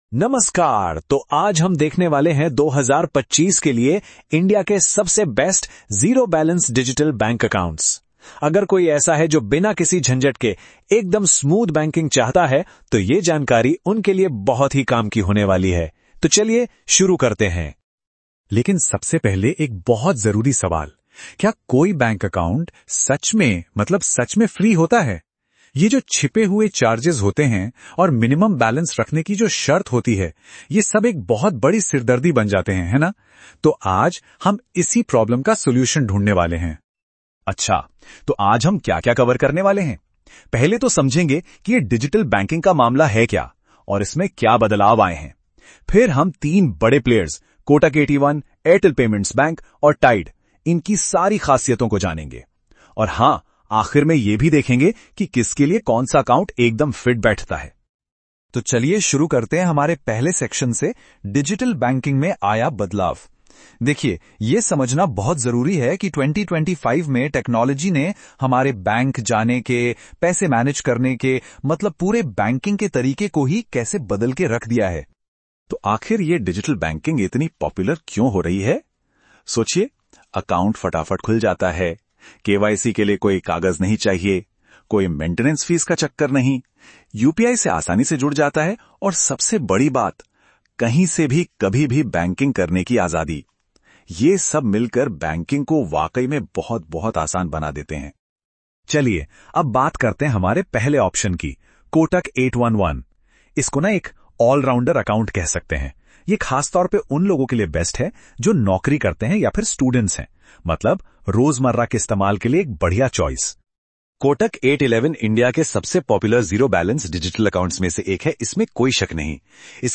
🎧 Prefer listening?Before you start reading, listen to a short Hindi audio overview of this post — it gives you a quick idea about the topic in just a minute.